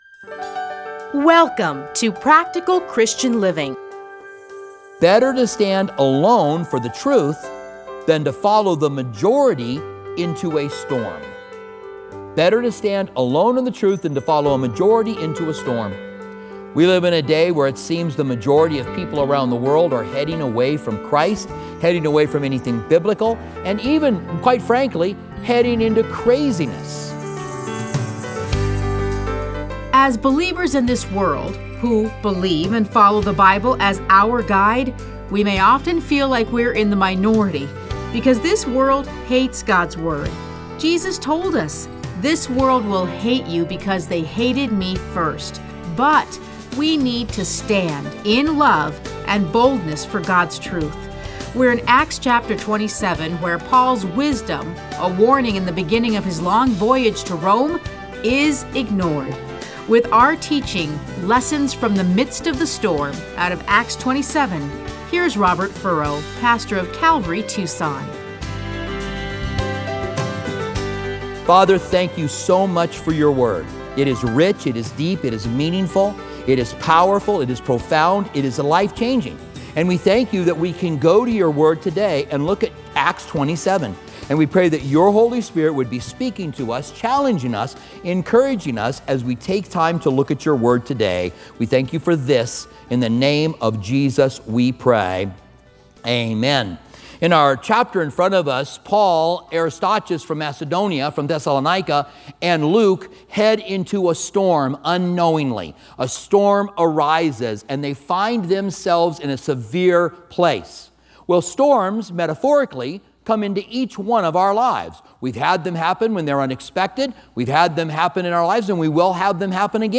Listen to a teaching from Acts 27:1-26.